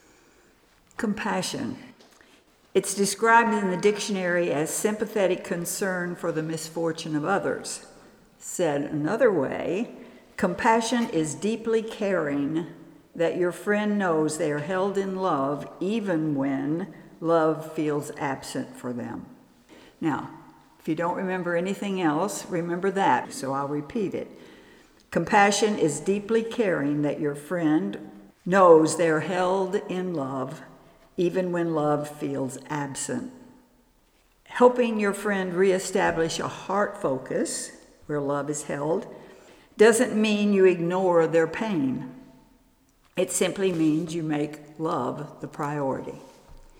Compassion – Workshop
Workshop Recordings